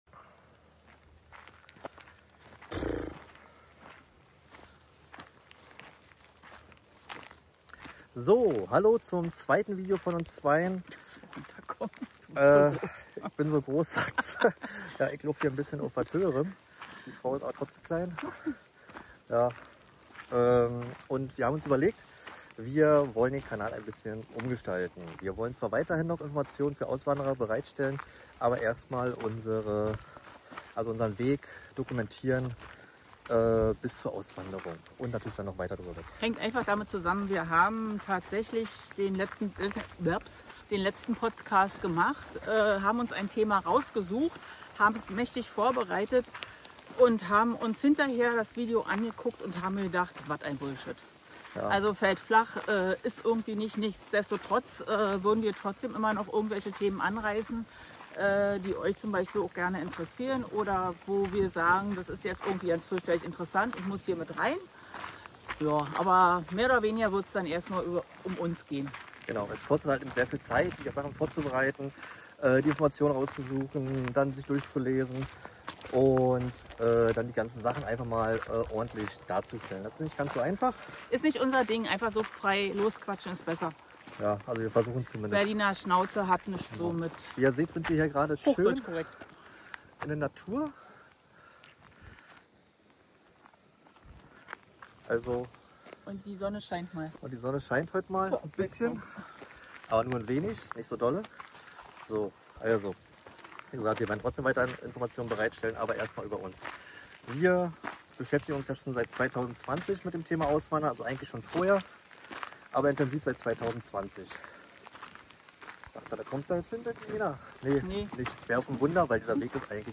Ehrlich, direkt und ohne Schönfärberei spricht das Paar über Entscheidungen, Zweifel und Motivation.